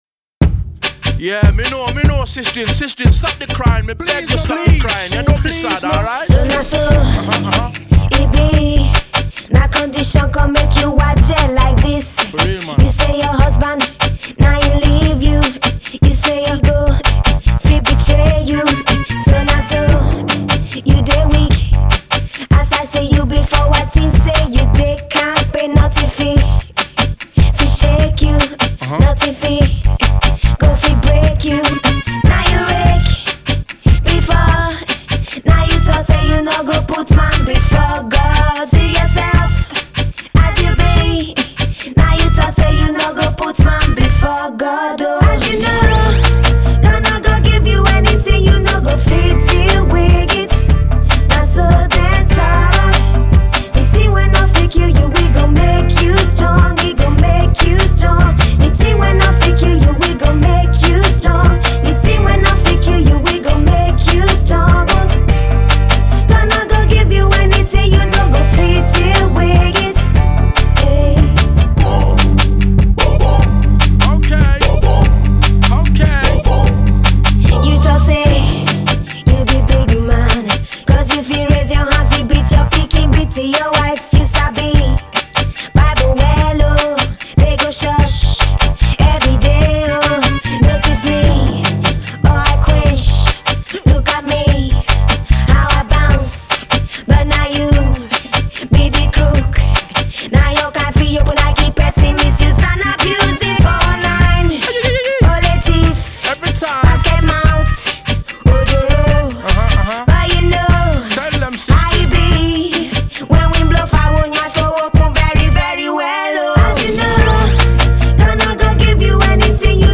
but she and the song speak gospel throughout.